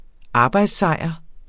arbejdssejr substantiv, fælleskøn Bøjning -en, -e, -ene Udtale [ ˈɑːbɑjds- ] Betydninger 1. sejr i sport som hovedsagelig skyldes en stor fysisk indsats Det var stort.